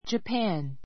Japan 小 dʒəpǽn ヂャ パ ン 固有名詞 日本 ✓ POINT 中国語読みの Jih （日） -pun （本）から.